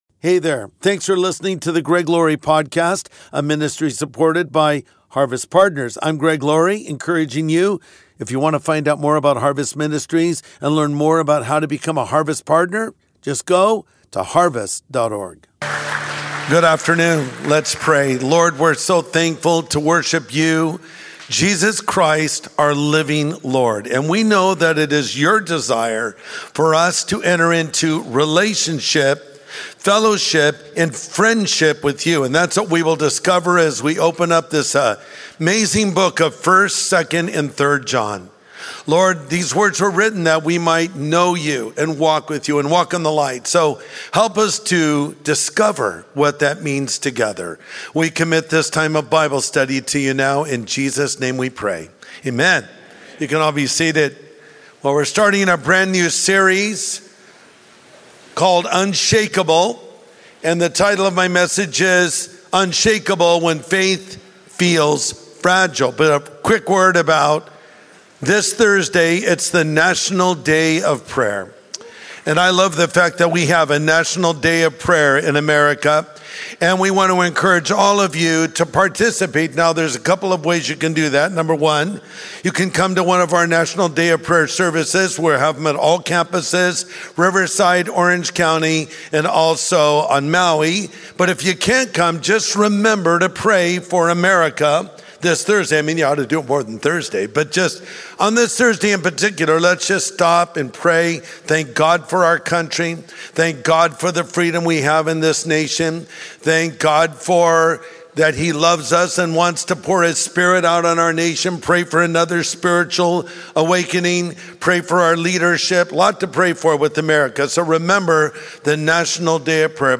When Faith Feels Fragile | Sunday Message